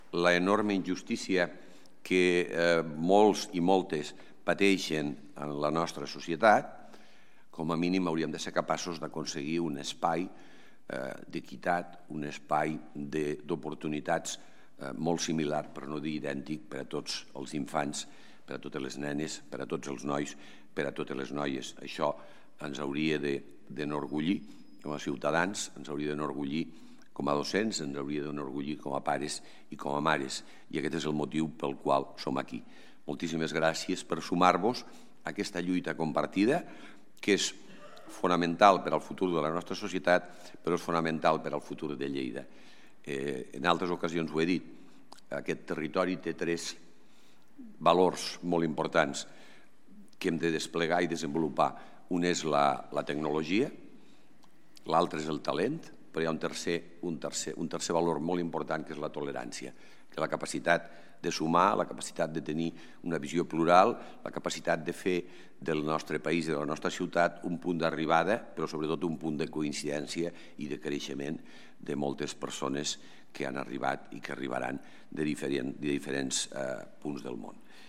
Tall de veu M.Pueyo Miquel Pueyo ha recordat que la lluita contra la segregació escolar és un repte important a escala educativa i de ciutat.
tall-de-veu-del-paer-en-cap-miquel-pueyo-sobre-la-signatura-del-pacte-local-per-a-la-prevencio-de-la-segregacio-escolar